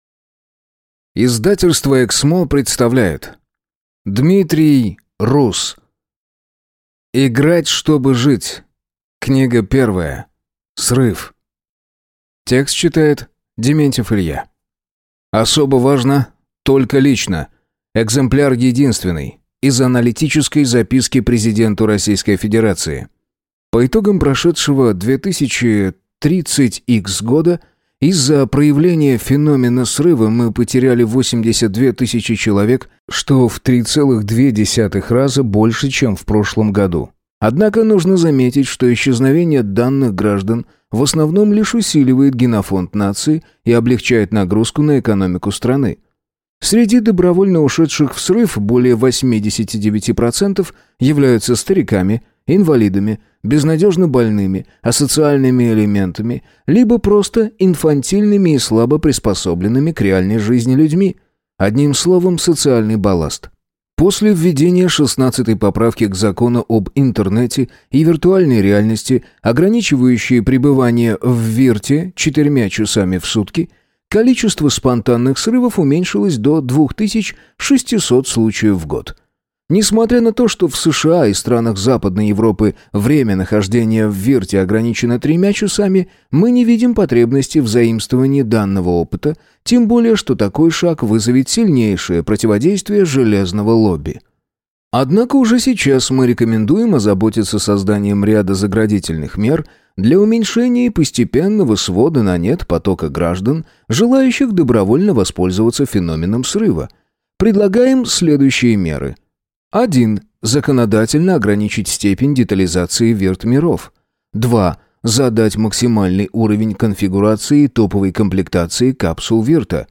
Аудиокнига Играть, чтобы жить. Книга 1. Срыв | Библиотека аудиокниг